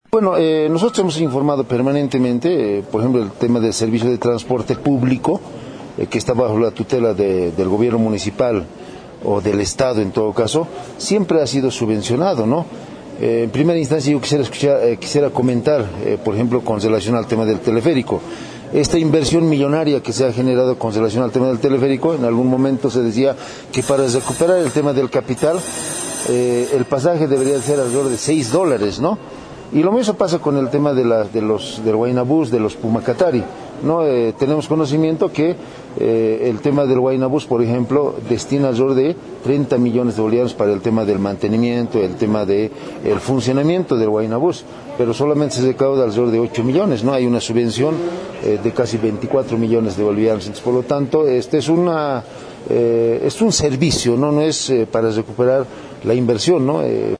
En respuesta a estas aseveraciones el concejal Oscar Huanca de Unidad Nacional (UN), manifestó que el transporte por cable Teleférico también es subvencionado y como tal también corresponde la subvención al transporte masivo municipal porque es un servicio a la población.